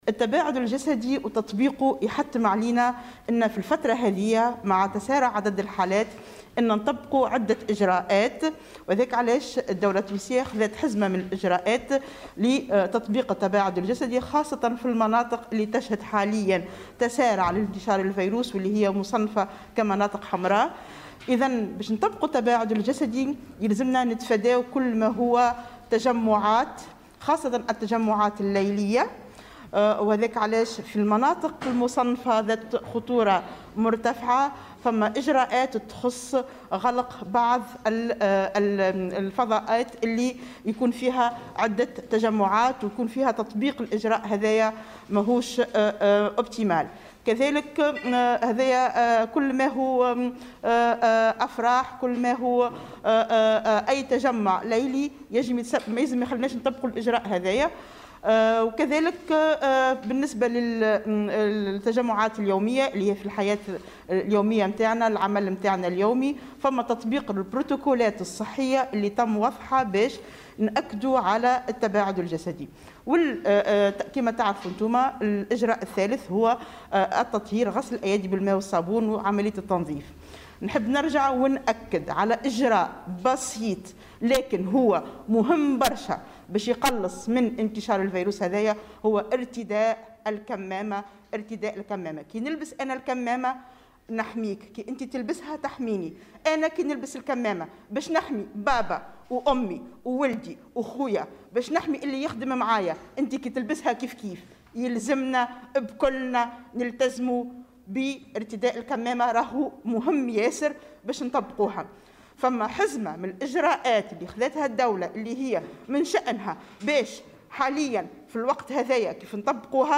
كما شدّدت أيضا خلال ندوة صحفية عقدتها وزارة الصحة اليوم، على ضرورة التباعد الجسدي و ارتداء الكمامات وغسل الايادي باستمرار، مشيرة إلى أن هذه الإجراءات من شأنها أن تقلّص من نسق تسارع انتشار فيروس "كورونا".